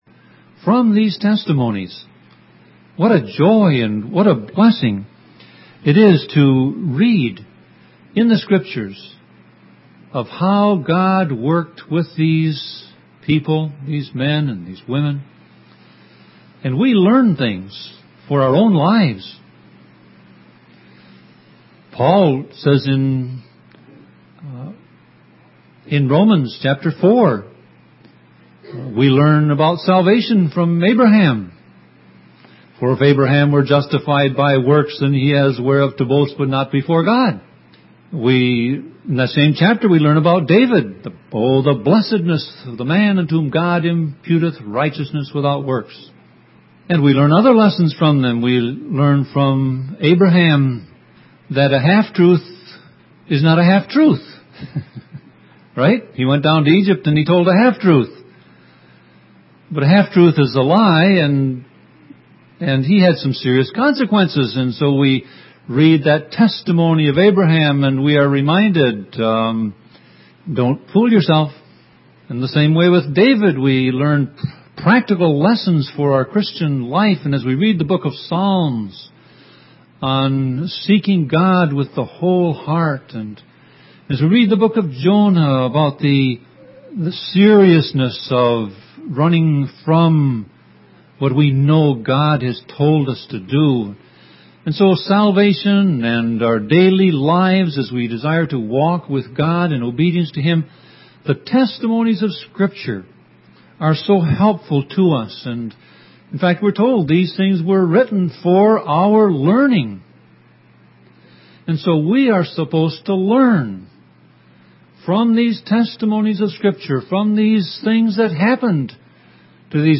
Sermon Audio Passage: Exodus 4 Service Type